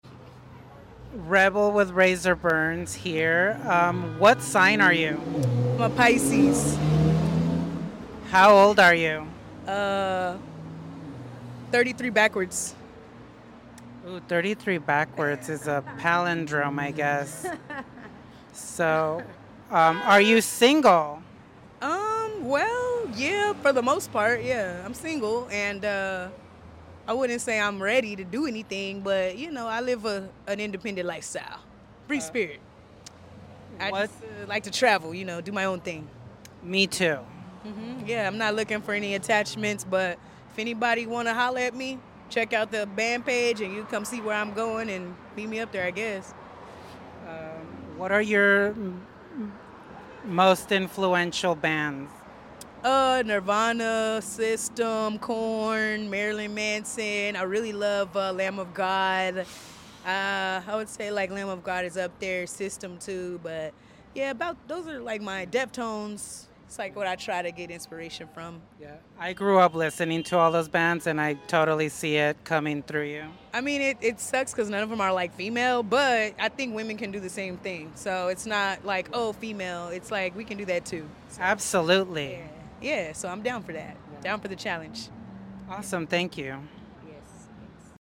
at Girlactic Music Festival